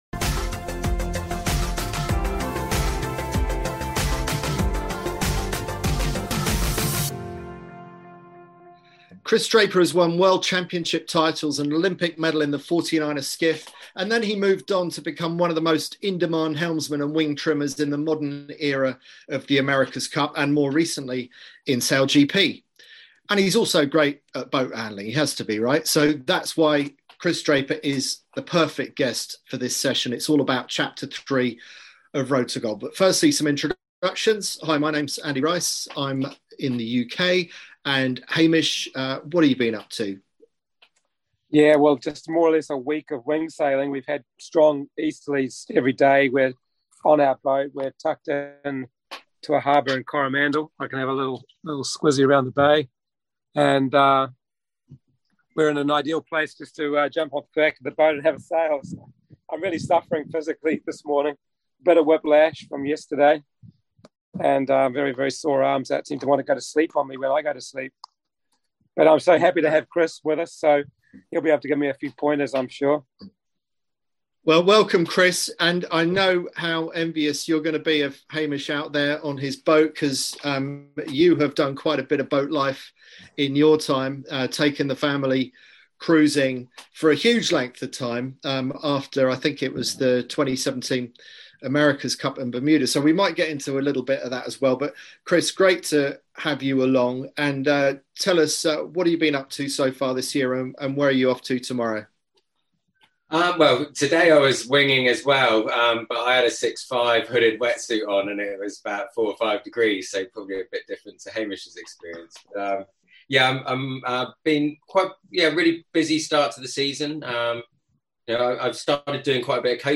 Our weekly Q&A sessions on Zoom, to answer your burning questions and enlighten you on your Road To Gold